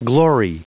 Prononciation du mot glory en anglais (fichier audio)
Prononciation du mot : glory